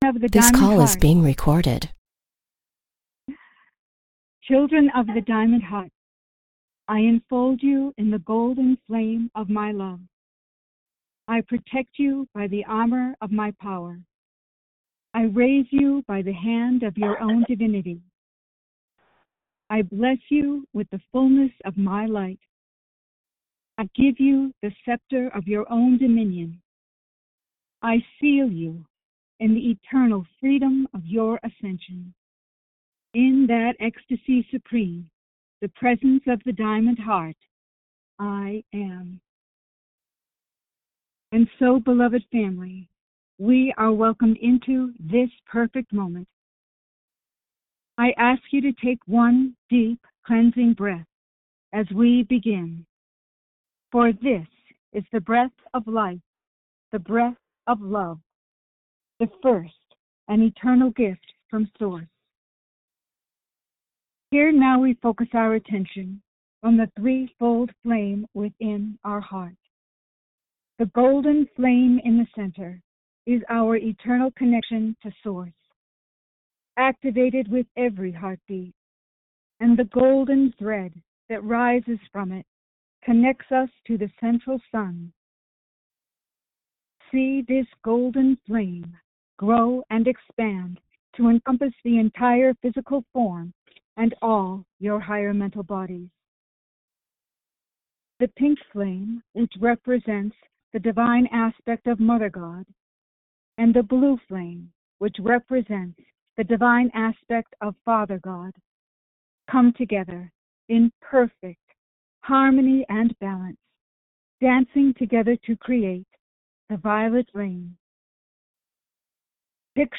Channeling – Minute (00:00) Channeling – Minute (00:00) Questions & Answers (00:00)